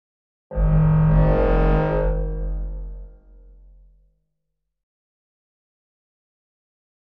Digital Digital Foghorn with LFE